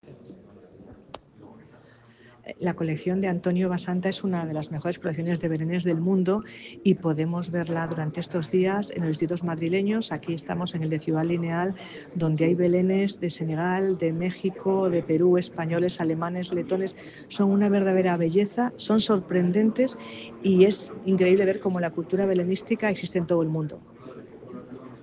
Declaraciones-de-Rivera-de-la-Cruz-en-la-exposicion-Belenes-del-Mundo.mp3